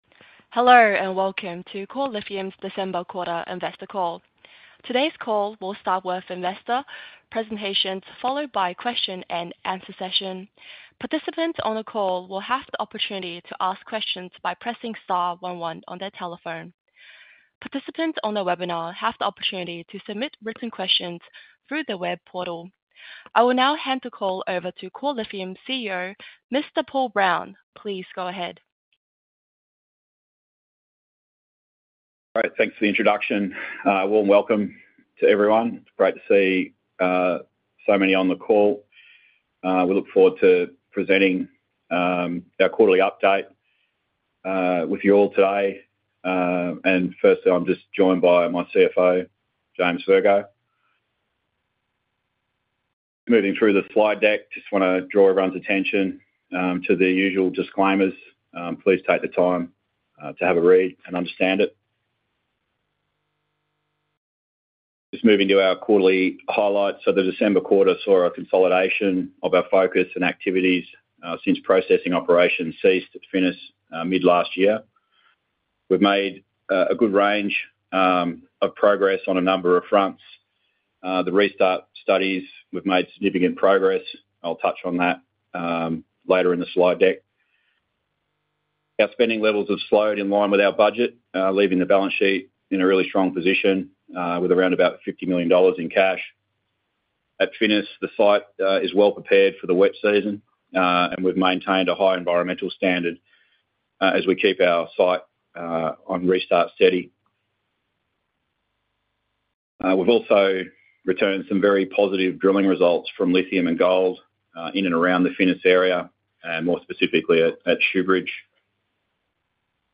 December Quarterly Investor Call Recording